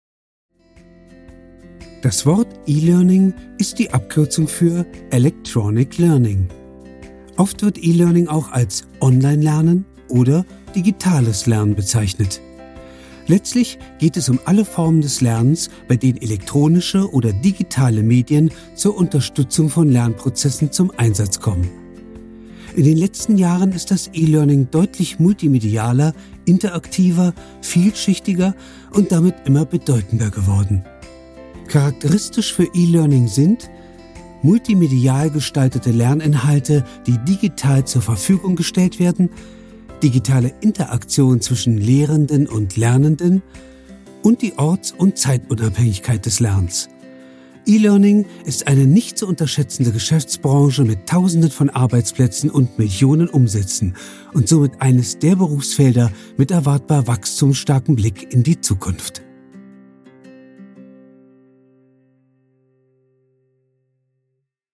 Gelernter Schauspieler. Glaubwürdig, ausdrucksstark, überzeugend, stimmlich variabel. Dialektfreie Mittellage, weich & samtig.
Sprechprobe: Industrie (Muttersprache):
Trained german actor. Credible, expressive, convincing, vocally variable. Dialect-free mid-range, soft & velvety.